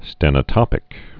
(stĕnə-tŏpĭk)